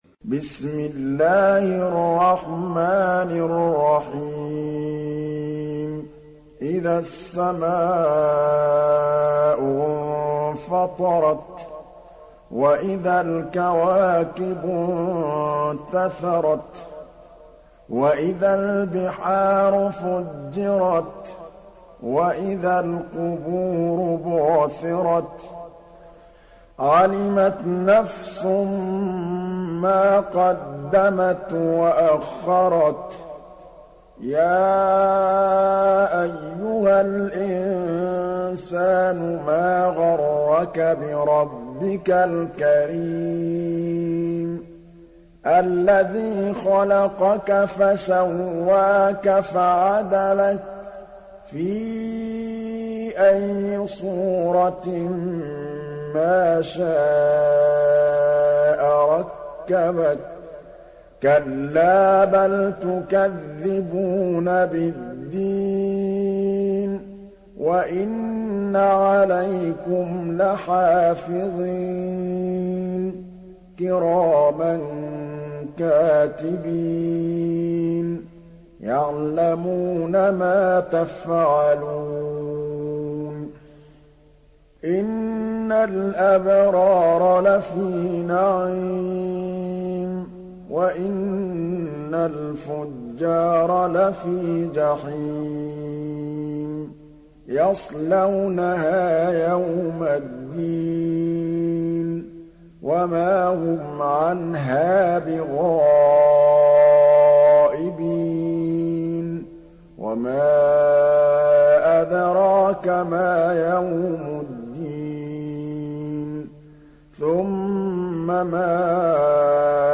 دانلود سوره الانفطار mp3 محمد محمود الطبلاوي روایت حفص از عاصم, قرآن را دانلود کنید و گوش کن mp3 ، لینک مستقیم کامل